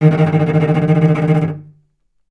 healing-soundscapes/Sound Banks/HSS_OP_Pack/Strings/cello/tremolo/vc_trm-D#3-mf.aif at f6aadab7241c7d7839cda3a5e6764c47edbe7bf2
vc_trm-D#3-mf.aif